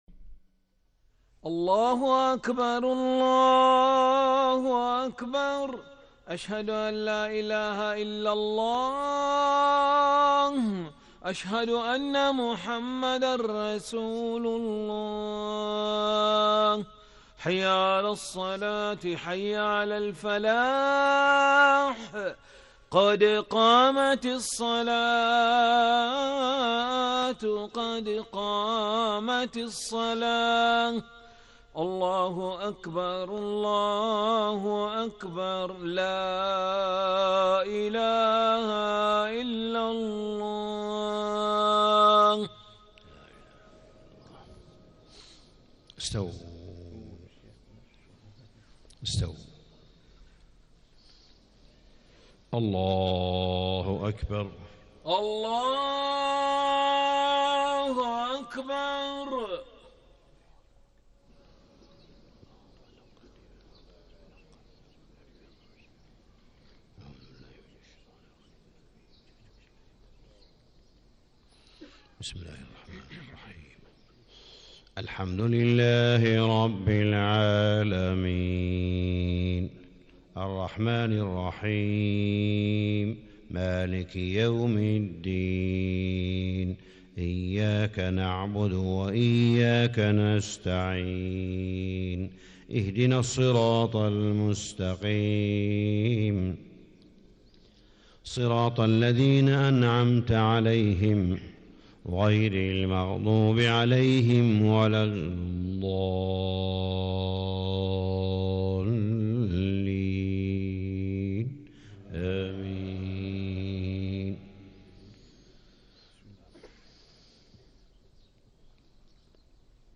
صلاة الفجر 14 ربيع الأول 1437هـ خواتيم سورة الأحزاب 56-73 > 1437 🕋 > الفروض - تلاوات الحرمين